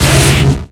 Cri de Sablaireau dans Pokémon X et Y.